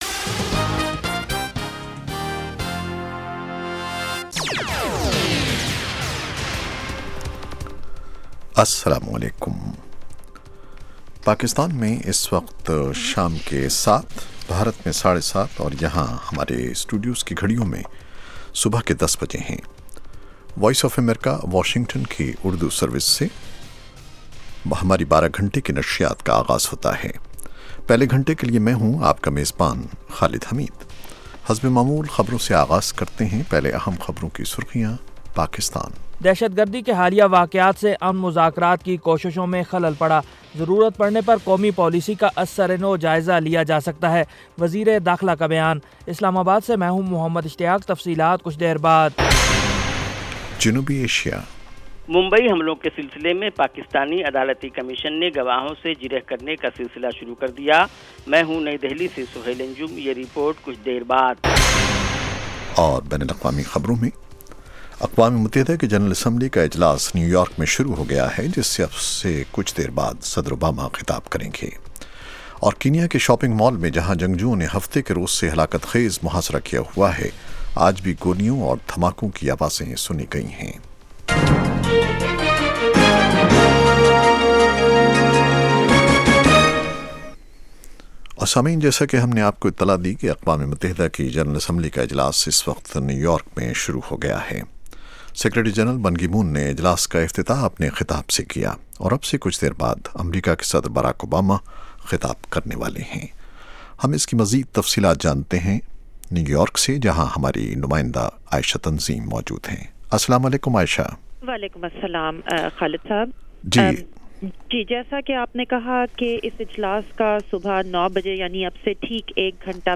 اقوامِ متحدہ - صدراوباما کی تقریر